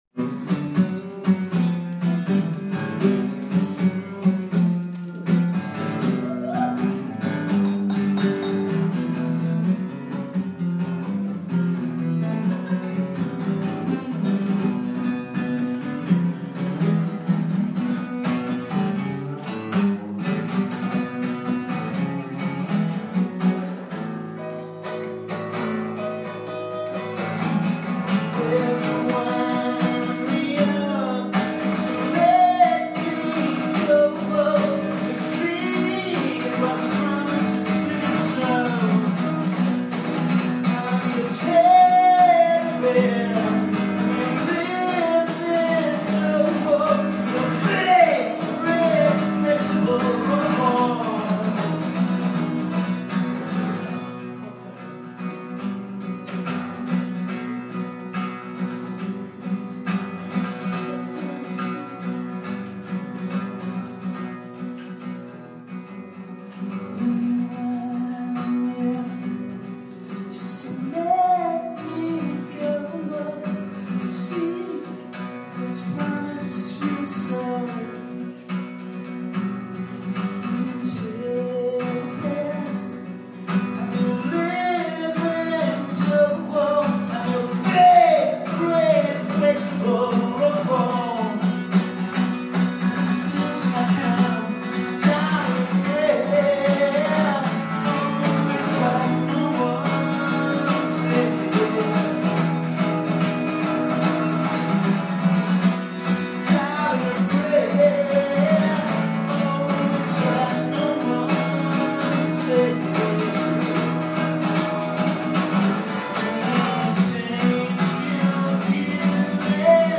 playing live